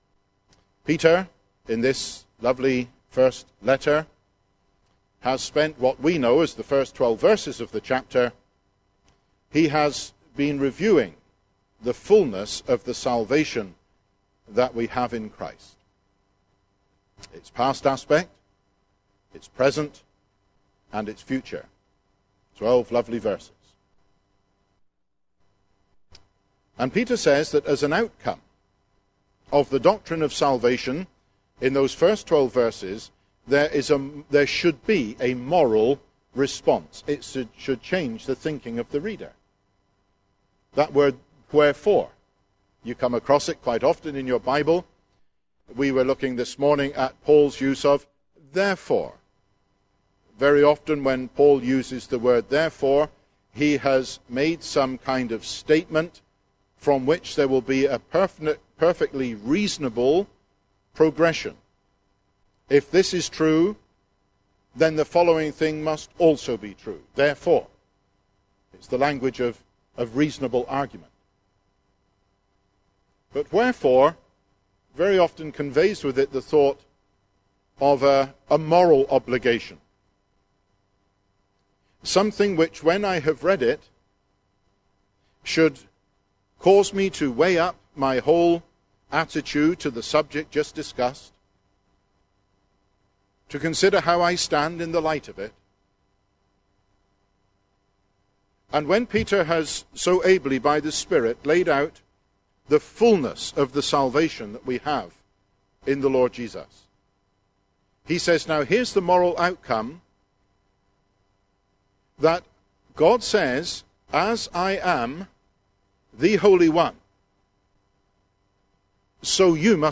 God has severed believers from the world and given them new appetites, new affections and new activities. (Recorded at the Vancouver Thanksgiving Conference, 2014)